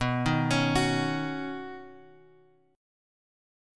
Badd9 chord {x 2 1 x 2 2} chord